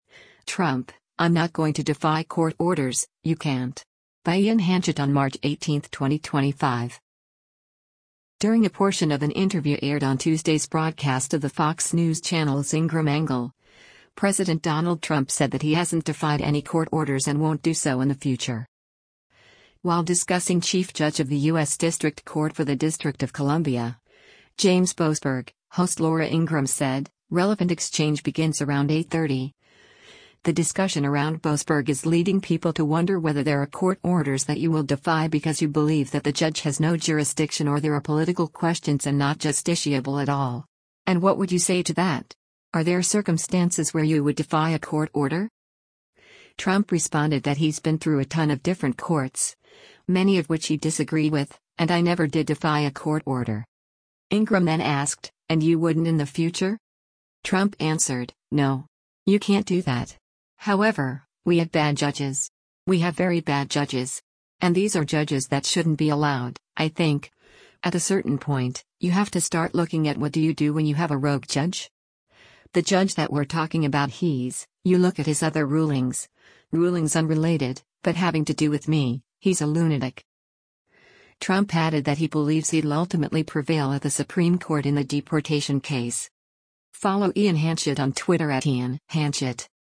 During a portion of an interview aired on Tuesday’s broadcast of the Fox News Channel’s “Ingraham Angle,” President Donald Trump said that he hasn’t defied any court orders and won’t do so in the future.